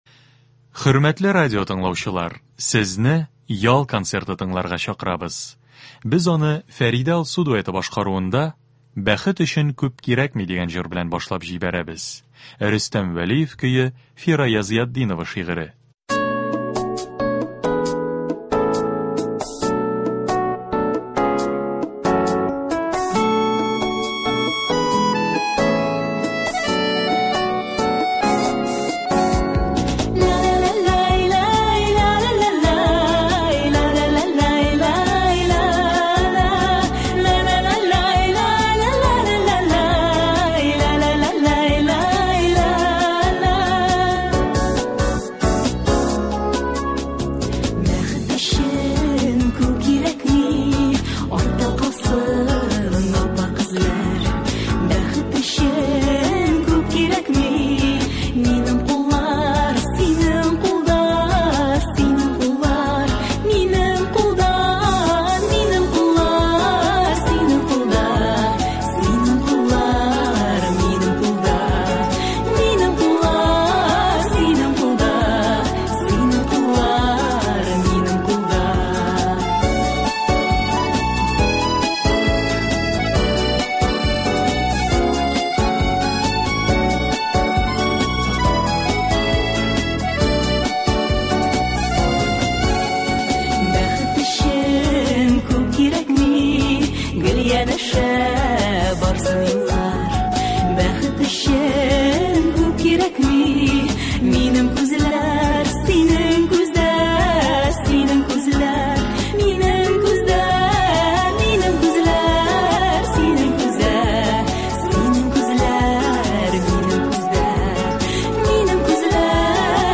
Без сезнең өчен, хөрмәтле радиотыңлаучыларыбыз, яхшы кәеф, күңел күтәренкелеге бирә торган концертларыбызны дәвам итәбез.